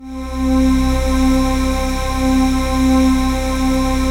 DM PAD2-82.wav